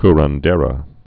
(krən-dârə)